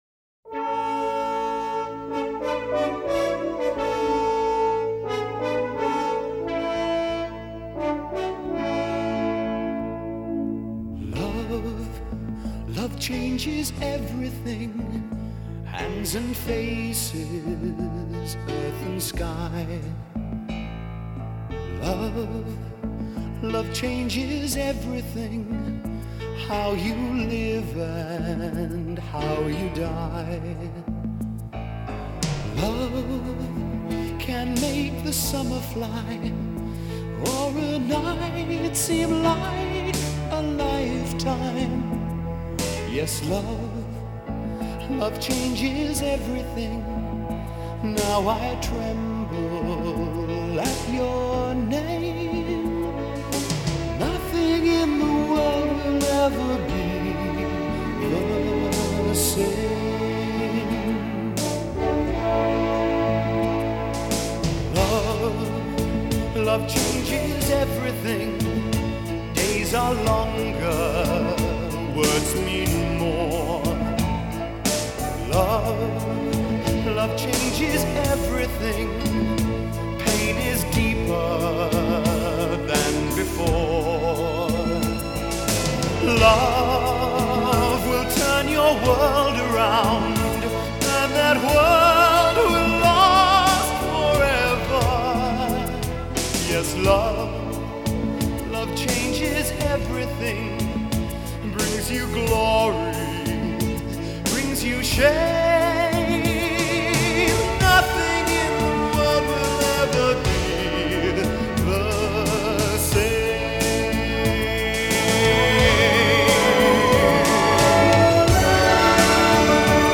明朗澄澈的录音效果